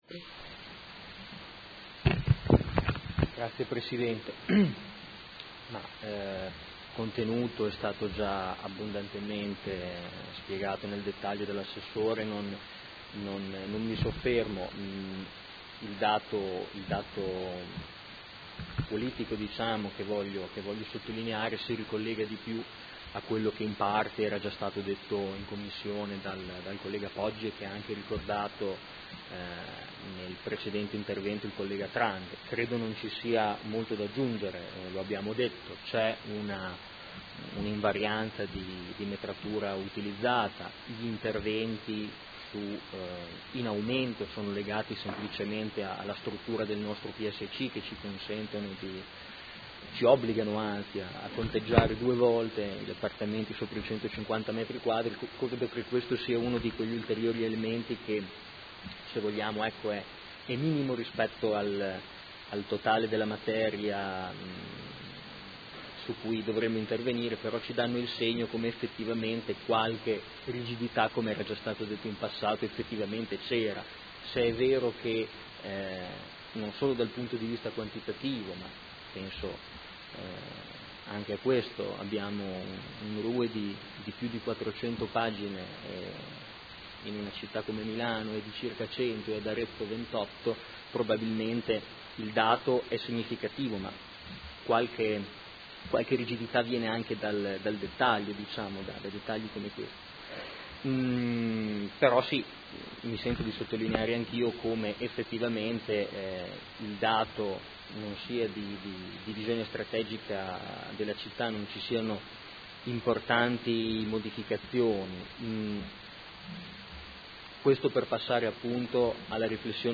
Marco Forghieri — Sito Audio Consiglio Comunale
Seduta del 22 ottobre. Proposta di deliberazione: Variante al Piano operativo comunale (POC) e al Regolamento urbanistico edilizio (RUE) – Controdeduzioni e approvazione ai sensi degli artt 33 e 34 della Legge regionale 20/2000 e s.m. Dibattito